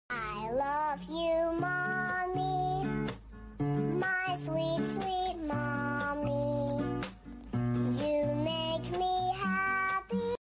singing baby sound effects free download